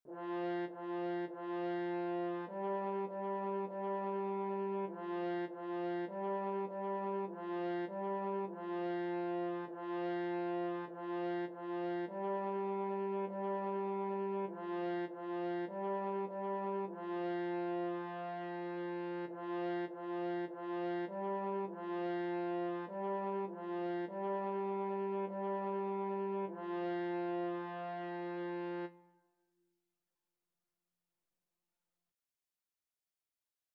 4/4 (View more 4/4 Music)
F4-G4
Instrument:
French Horn  (View more Beginners French Horn Music)
Classical (View more Classical French Horn Music)